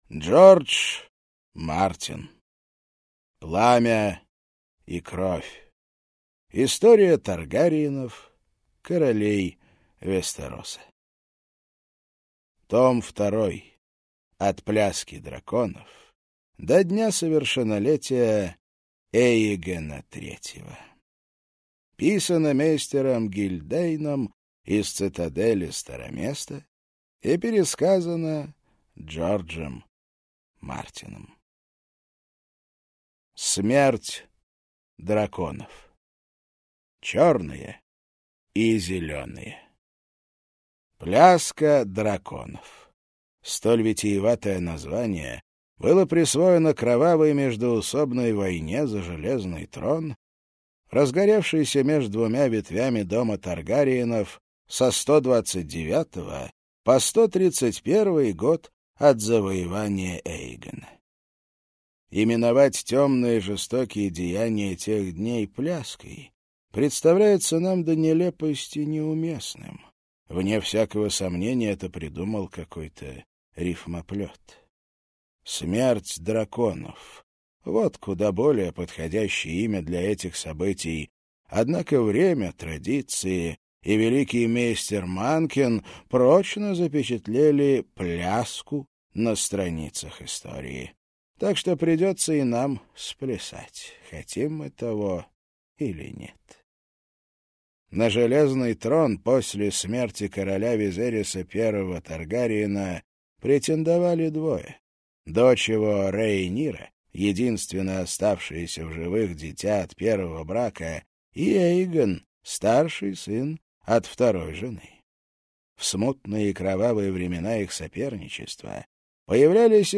Аудиокнига Пламя и кровь.